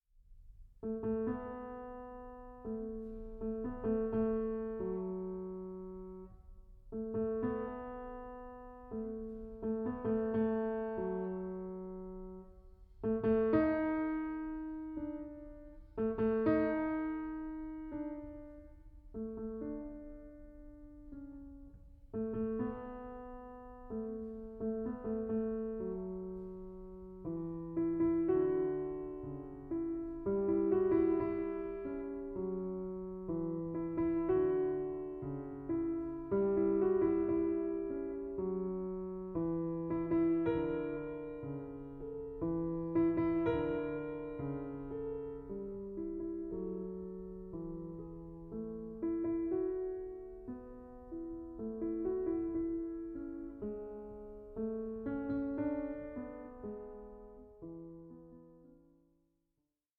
15) No. 8, in F-Sharp Minor: Prelude 1:12